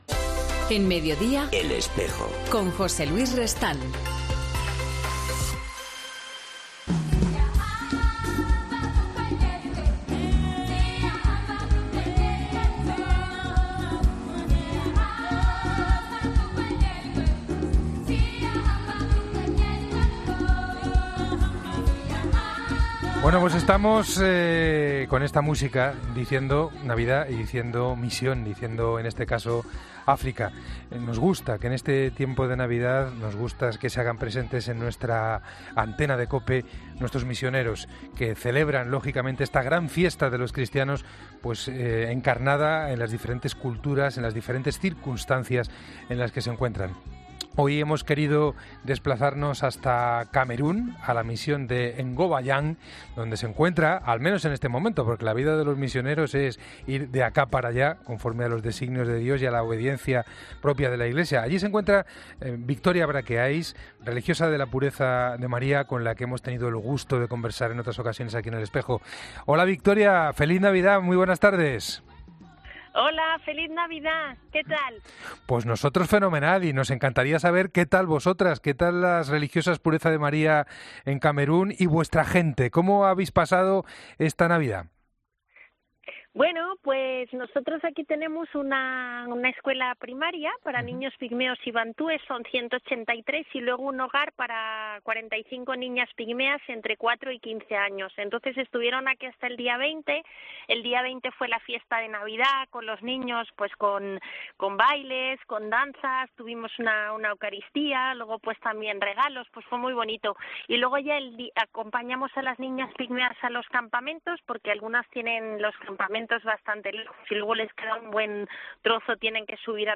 Hoy ha atendido la llamada de El Espejo desde Camerún, donde pasa unas semanas haciendo un seguimiento de un proyecto de ampliación de una escuela que apoya Manos Unidas: "Aquí tenemos una Escuela Primaria para niños pigmeos y bantúes, en total de 183 niños.